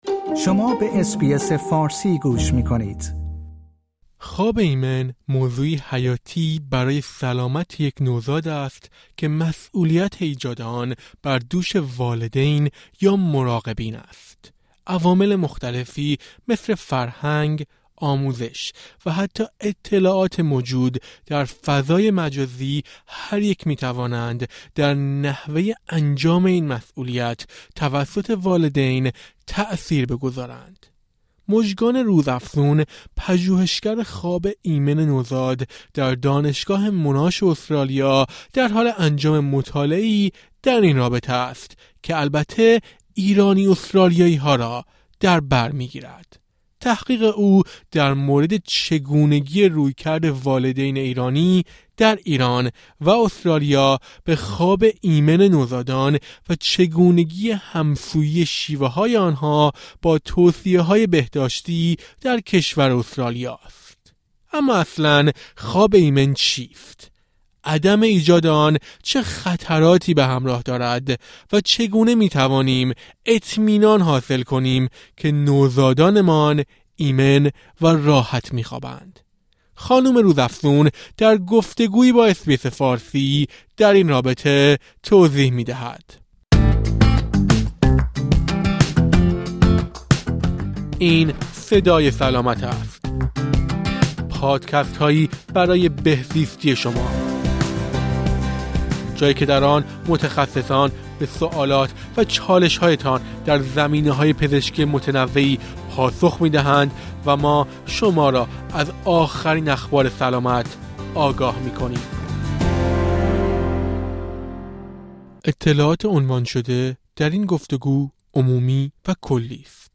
چگونه می‌توانیم اطمینان حاصل کنیم که نوزادانمان ایمن و راحت می‌خوابند؟ یک متخصص خواب ایمن نوزاد، در گفت‌وگویی با اس‌بی‌اس فارسی در این رابطه توضیح می‌دهد.